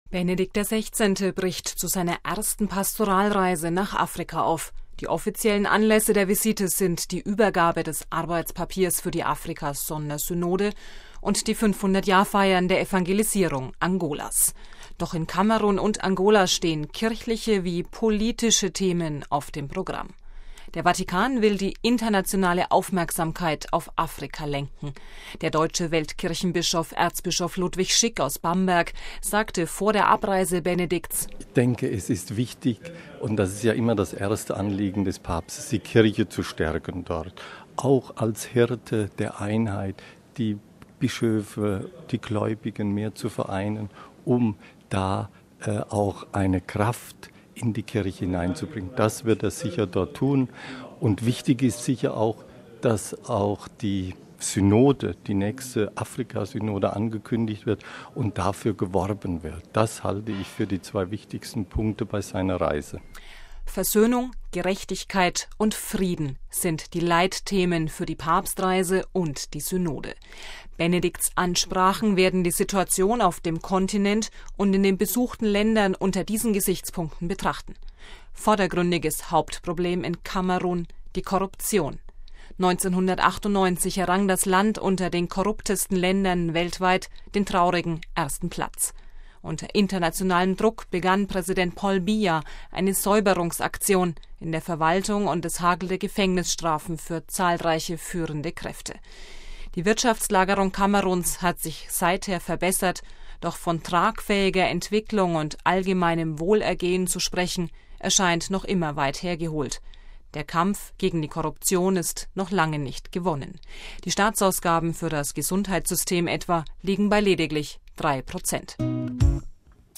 Sollte Benedikt XVI. das jetzt aufgreifen? Erzbischof Schick: